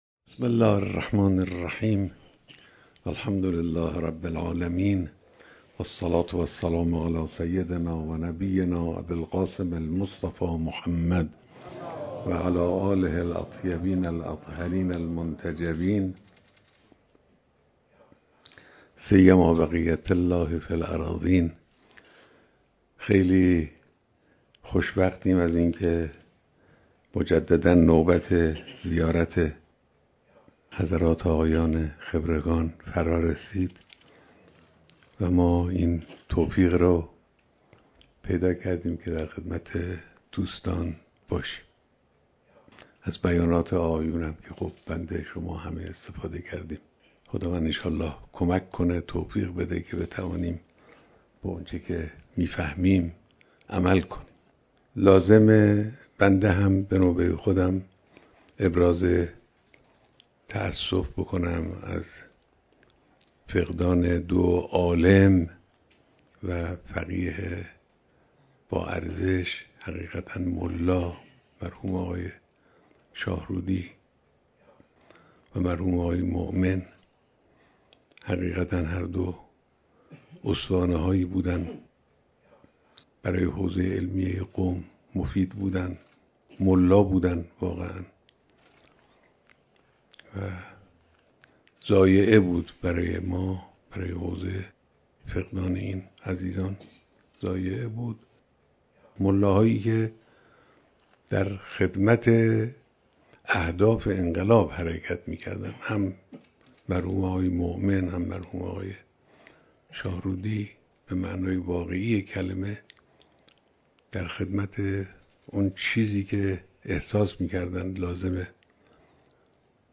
بیانات در دیدار رئیس و اعضای مجلس خبرگان رهبری